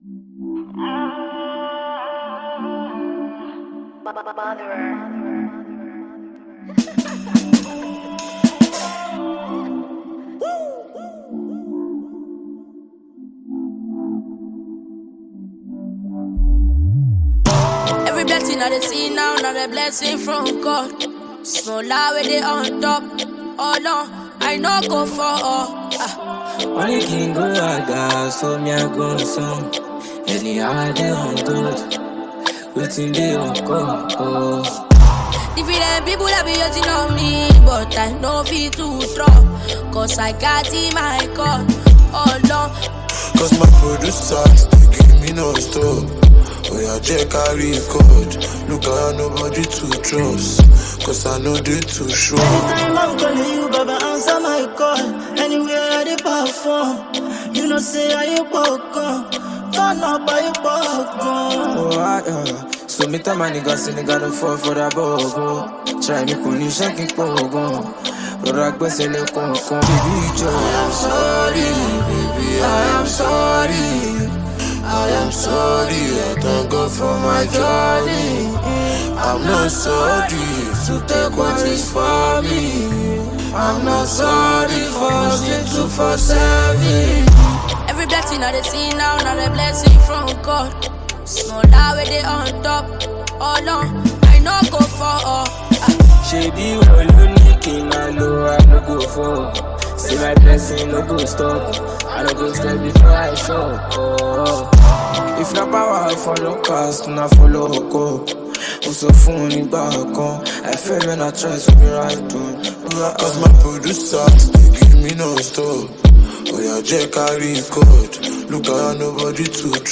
who in turns splits in some hot verses